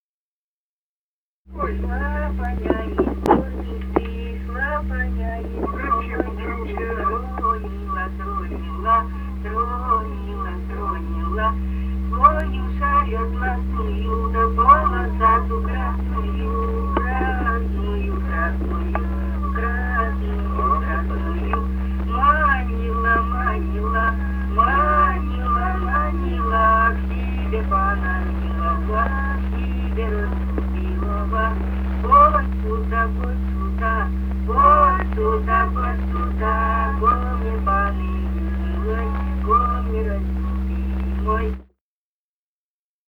Русские народные песни Красноярского края.
« Шла панья из горницы» (вечёрочная). с. Денисово Дзержинского района. Пела группа колхозников